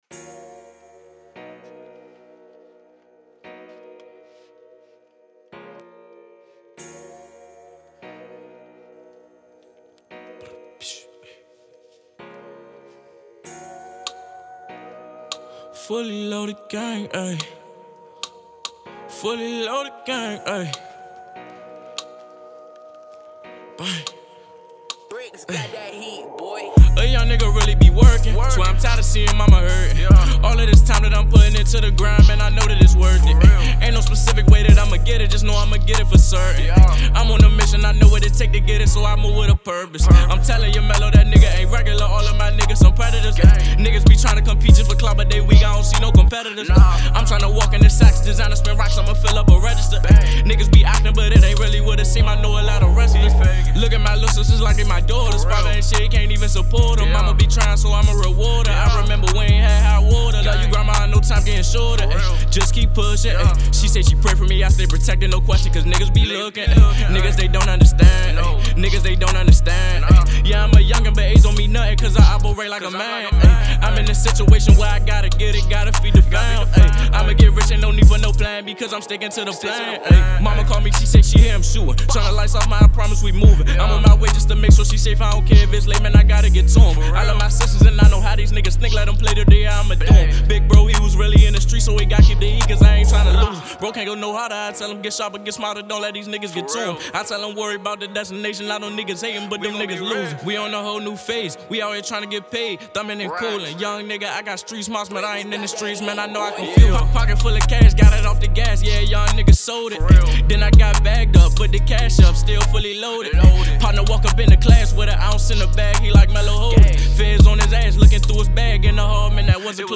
An Indie Hip Hop Artist from Washington, DC.